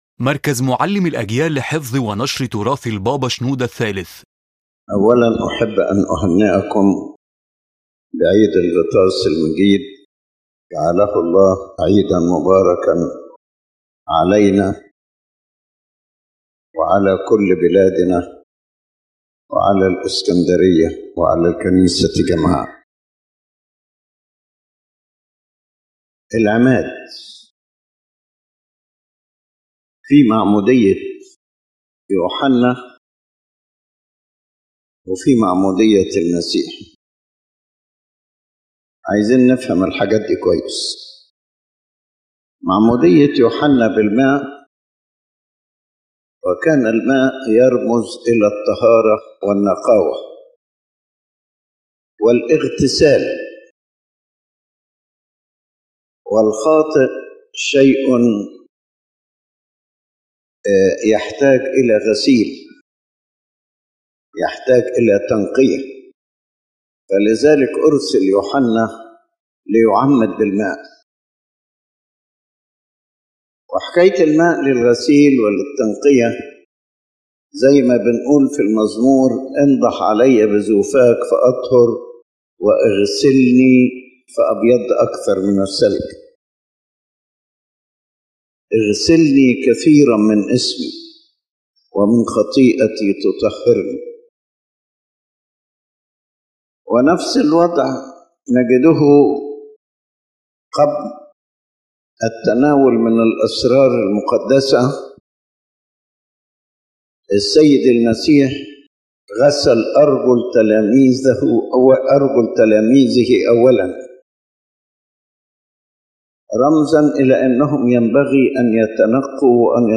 عظة قداس عيد الغطاس المجيد